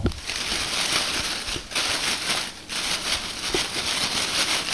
›  Rascheln [WAV | 102 KB] beim Verstecken unter den Bäumen und Sträuchern.
SLH_rascheln.wav